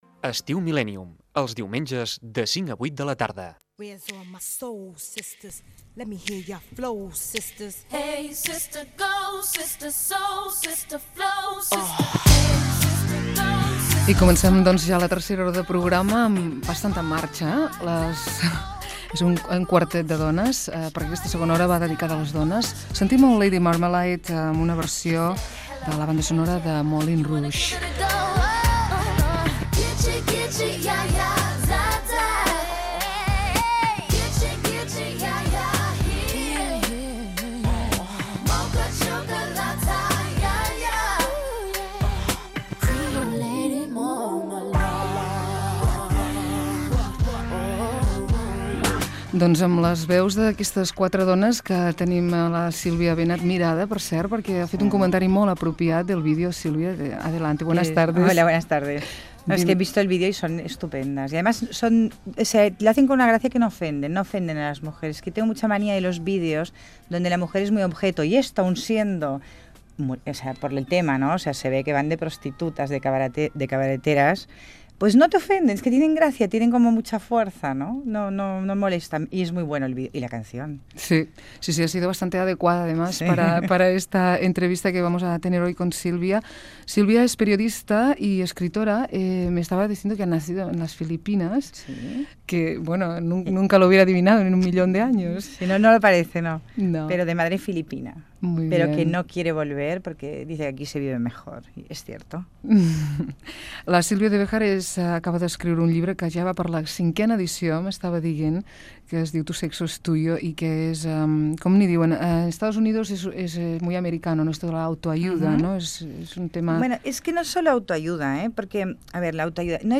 Entreteniment
Fragment extret de l'arxiu sonor de COM Ràdio